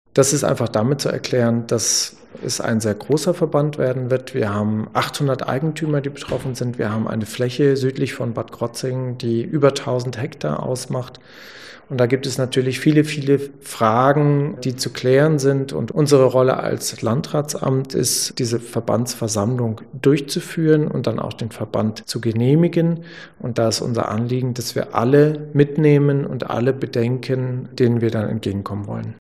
Martin Barth, Erster Landesbeamter beim Landratsamt Breisgau-Hochschwarzwald: